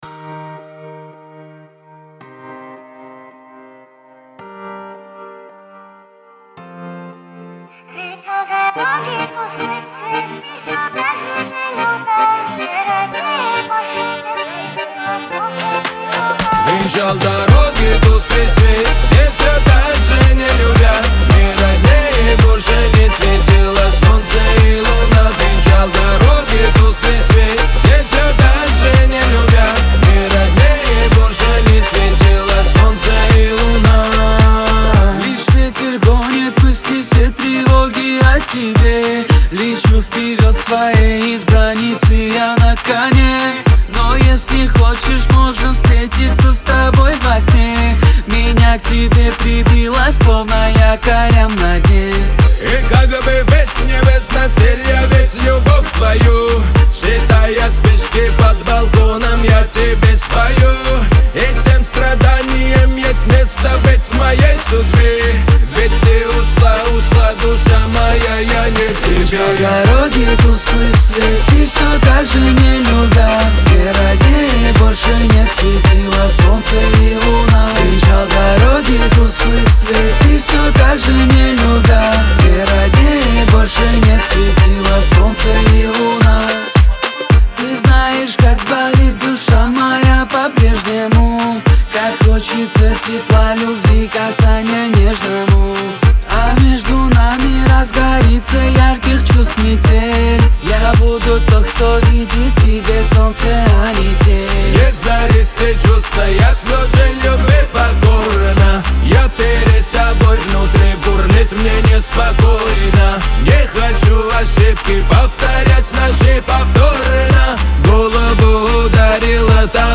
Кавказ поп
Лирика